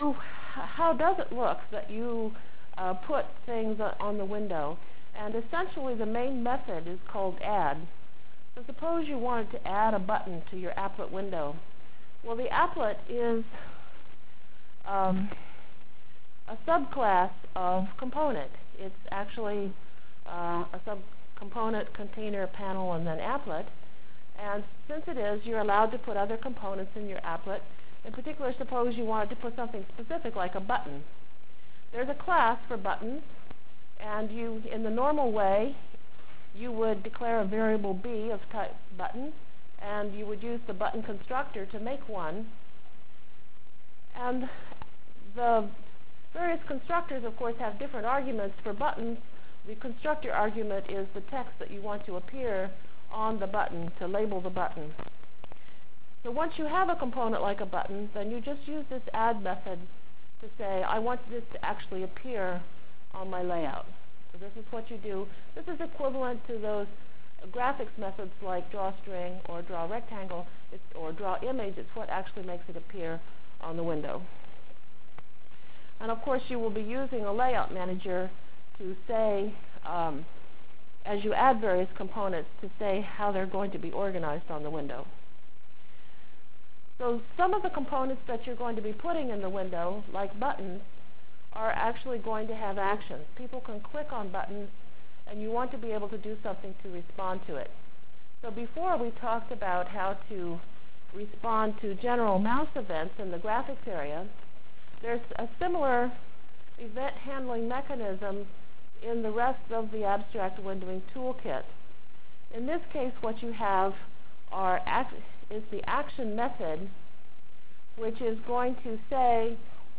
From Feb 3 Delivered Lecture for Course CPS616 -- Java Lecture 4 -- AWT Through I/O CPS616 spring 1997 -- Feb 3 1997.